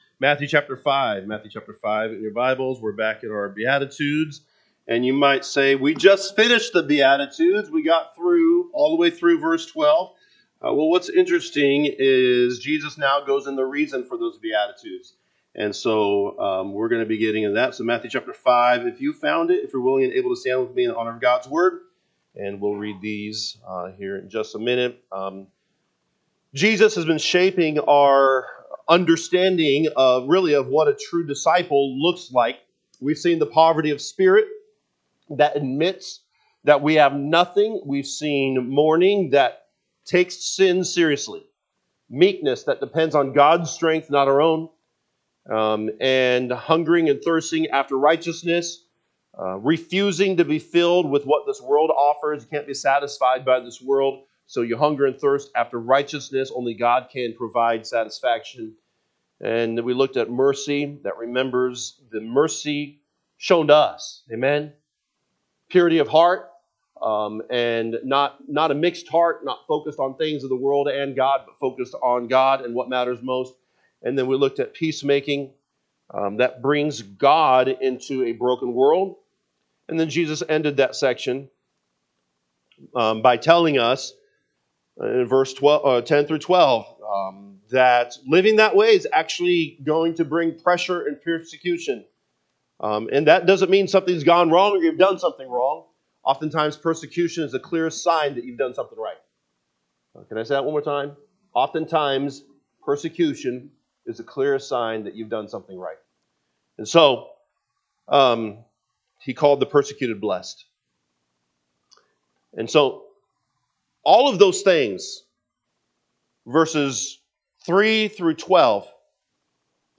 March 22, 2026 am Service Matthew 5:3-13 (KJB) 3 Blessed are the poor in spirit: for theirs is the kingdom of heaven. 4 Blessed are they that mourn: for they shall be comforted. 5 Bl…